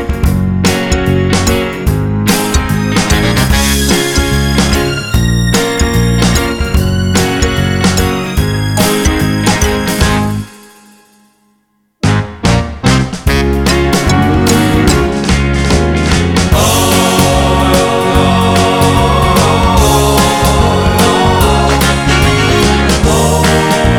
With Harmony Pop (1960s) 1:53 Buy £1.50